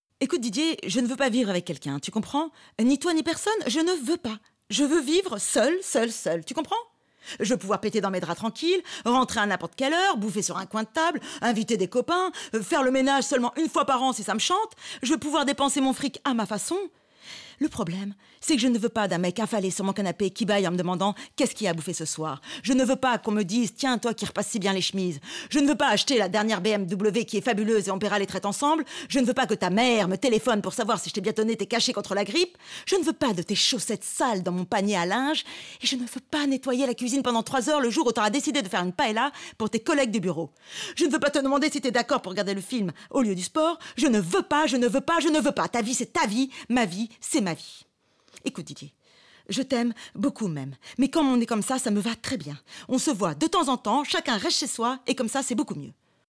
Voix jouée dynamique - La Crise, Coline Serreau